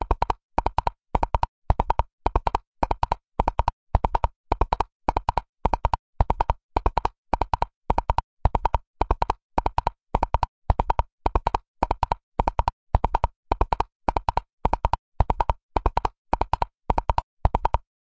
马奔腾（椰壳）版本1
描述：简单记录椰子壳的声音类似于马驰骋
标签： 盖洛普 奔腾
声道立体声